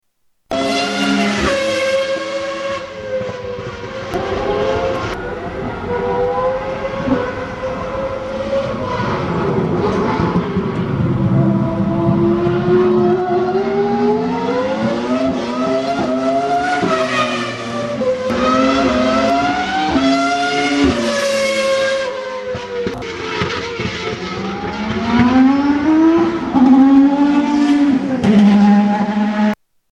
Ferrari F1-f3 Sweden